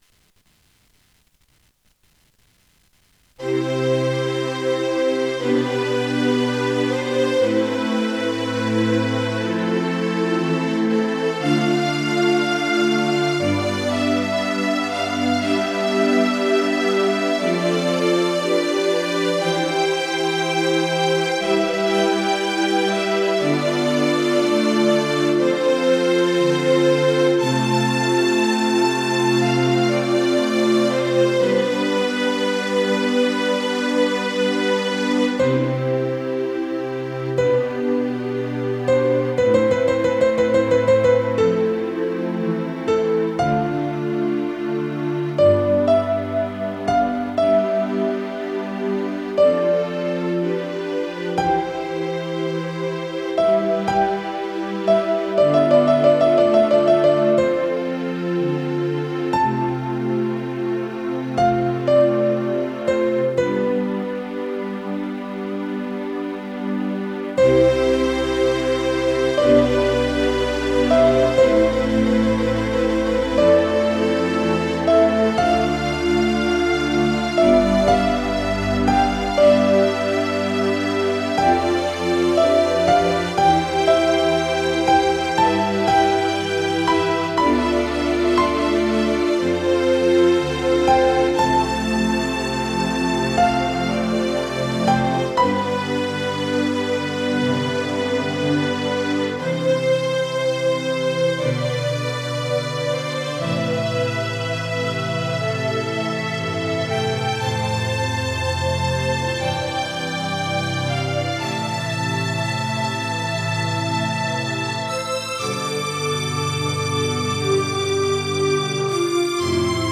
４．個人の作った曲（Desktop Music)　の紹介